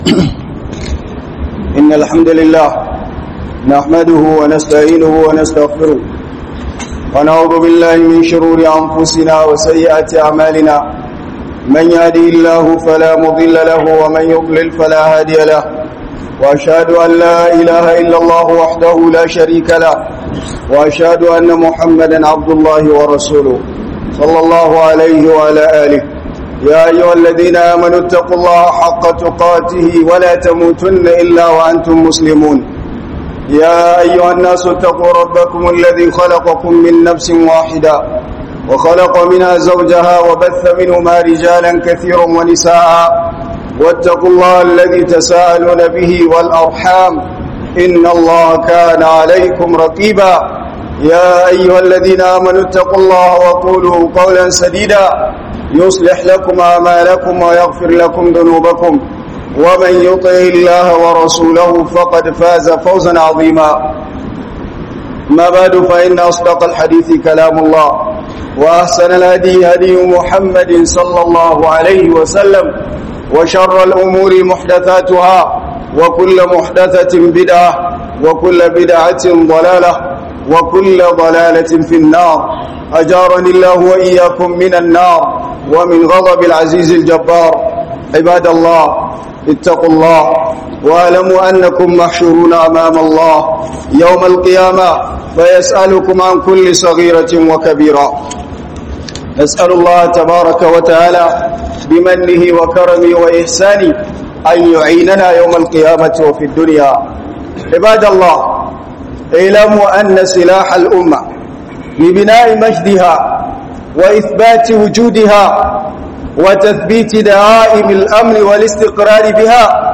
hudubar juma'a Daga masallaci Sahaba 11 juillet 2025